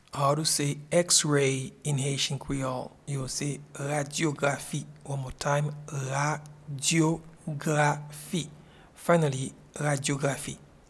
Pronunciation and Transcript:
X-ray-in-Haitian-Creole-Radyografi.mp3